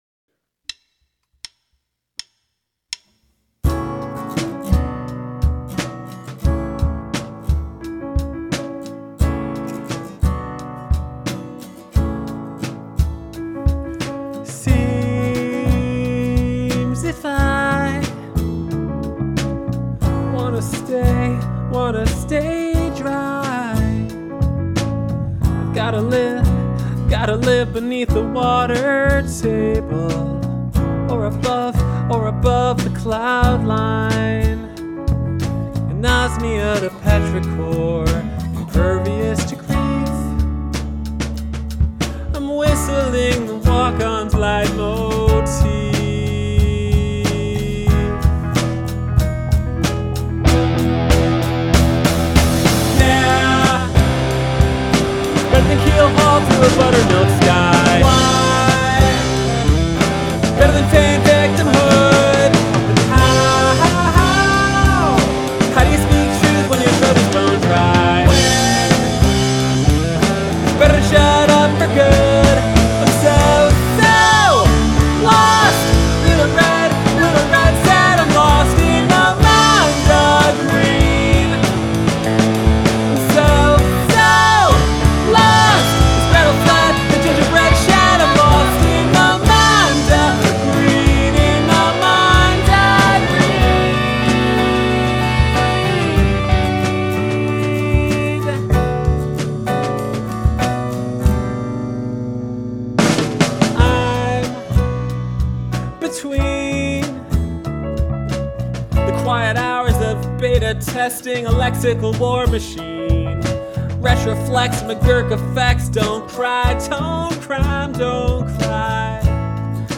additional vocals
drums
piano